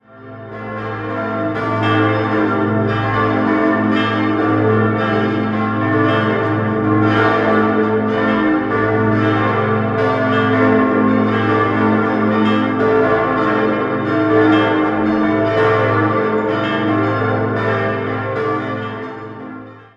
6-stimmiges Geläute: a°-c'-e'-g'-a'-c'' Die Glocken 3, 4 und 6 wurden 1750 von Nicolaus (II) und Claudius Arnoldt gegossen, Glocke 5 im Jahr 1951 von Kurtz und die beiden großen von Bachert im Jahr 1961.